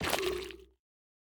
Minecraft Version Minecraft Version snapshot Latest Release | Latest Snapshot snapshot / assets / minecraft / sounds / block / sculk_sensor / place2.ogg Compare With Compare With Latest Release | Latest Snapshot